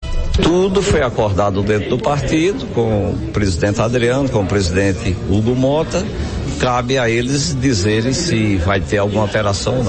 O deputado estadual Branco Mendes (Republicanos) usou a tribuna da Assembleia Legislativa da Paraíba (ALPB) durante a sessão ordinária desta terça-feira (27) para apelar ao seu colega, Adriano Galdino (Republicanos), que mantenha o acordo que lhe garante ser o candidato do partido para o 1º biênio da Assembleia Legislativa da Paraíba (ALPB).